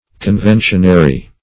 Meaning of conventionary. conventionary synonyms, pronunciation, spelling and more from Free Dictionary.
Search Result for " conventionary" : The Collaborative International Dictionary of English v.0.48: Conventionary \Con*ven"tion*a*ry\, a. Acting under contract; settled by express agreement; as, conventionary tenants.